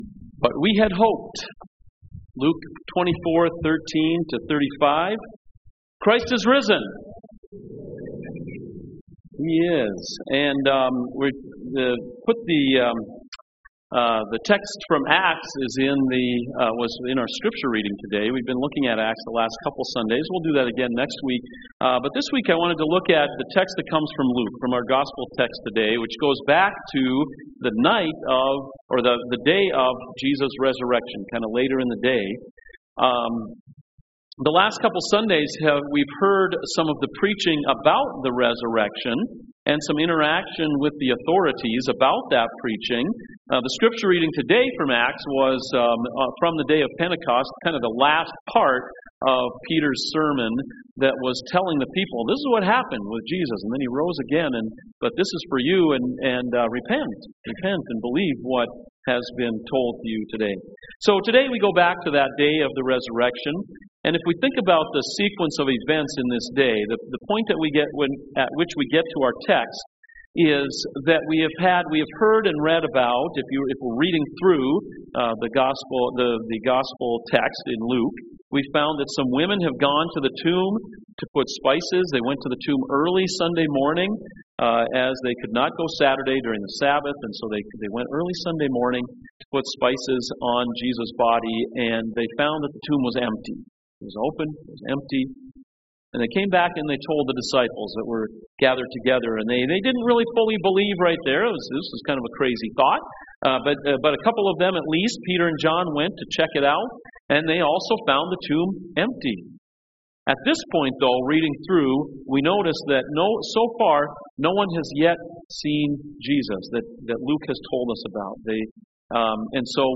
CoJ Sermons But We Had Hoped…»